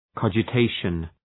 Προφορά
{,kɒdʒə’teıʃən}